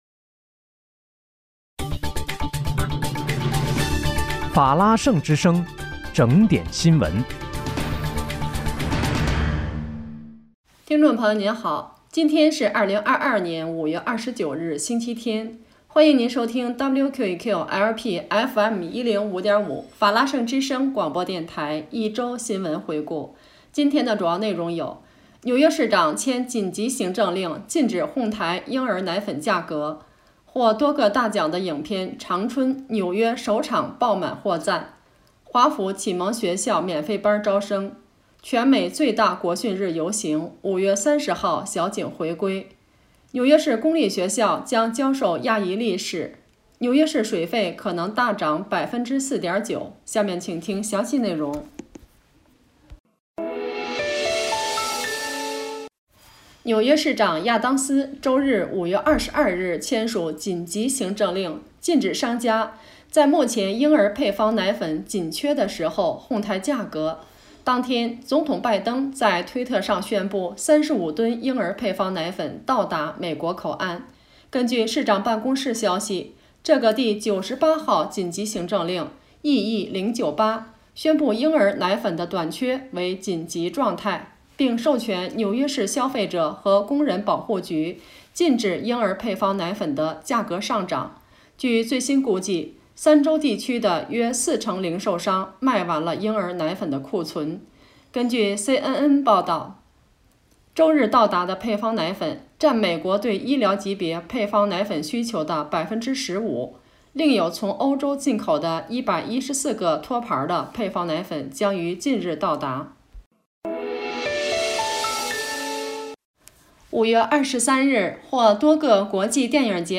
5月29日（星期日）纽约整点新闻